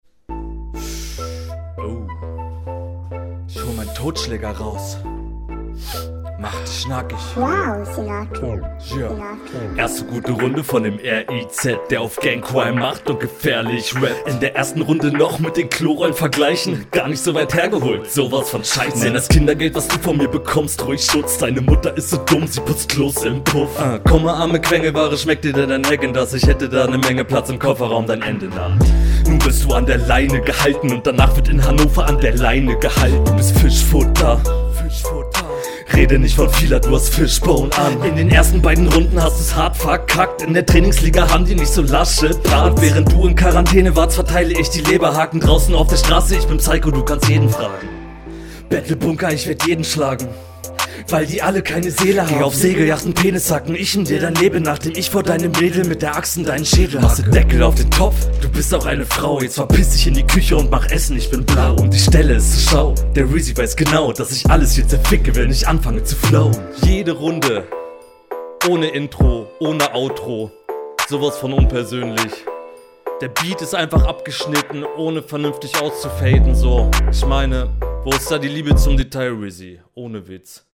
Abmische sagt mir hier nicht zu.